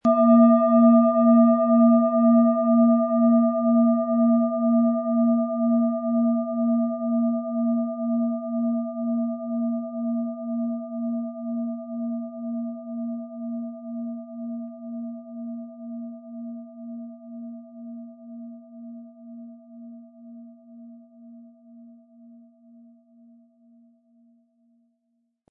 Hopi Herzton
SchalenformBihar
MaterialBronze